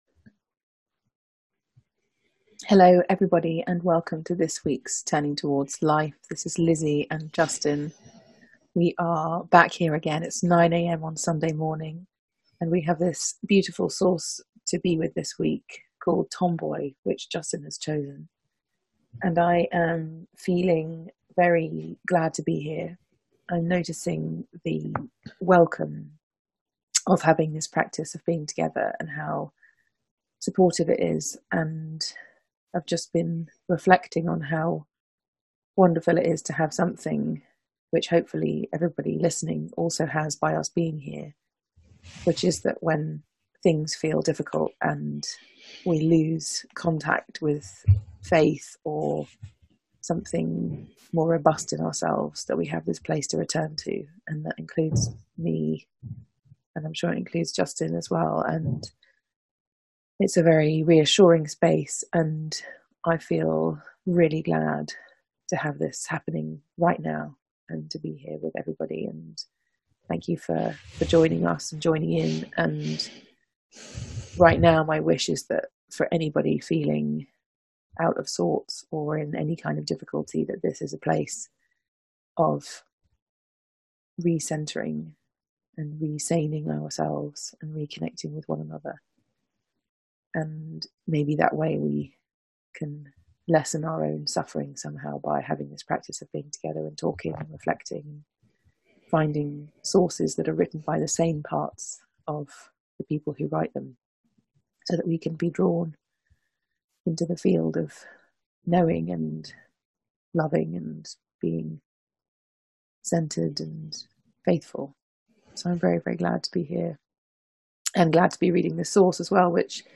a weekly live 30 minute conversation